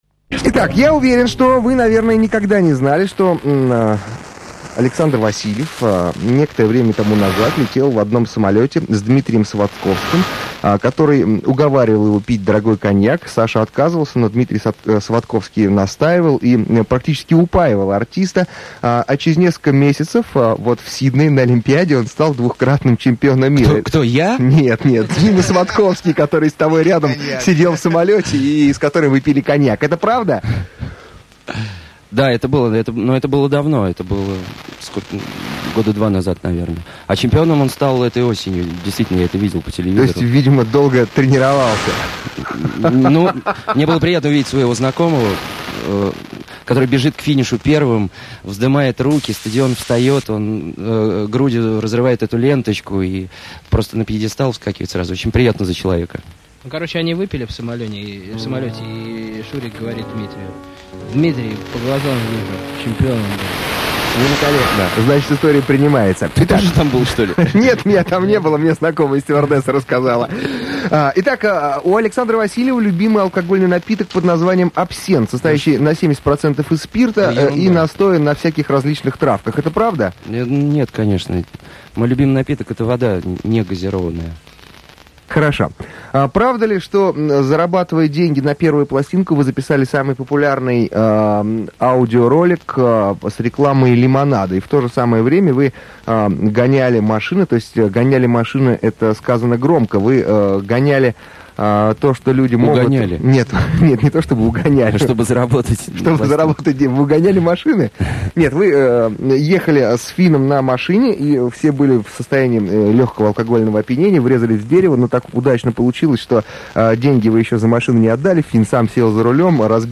Интервью, часть 1 + песня про 'елочку' 3,554 Мб 7:24 мин 64/44